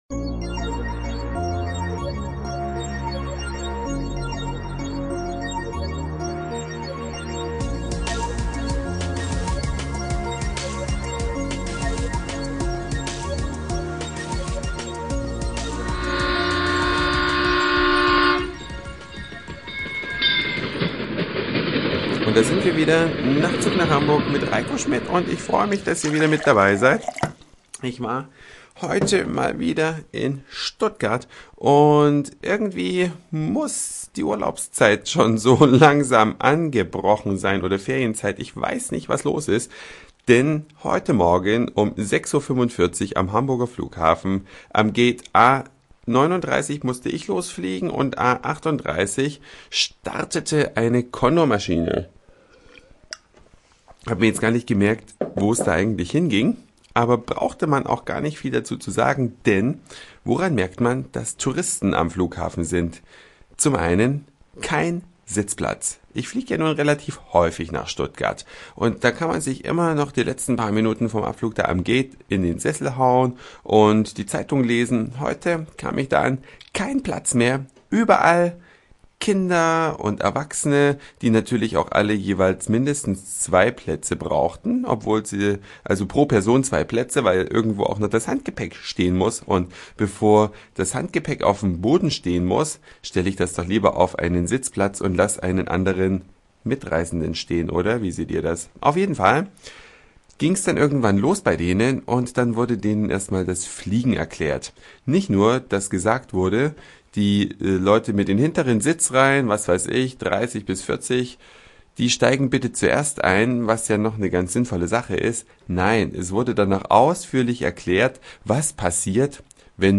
Ein Morgen am Flughafen und Beobachtungen kurz nach der Landung.